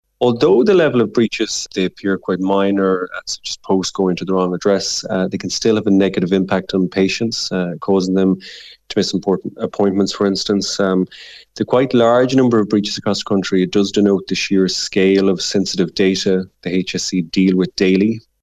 Solicitor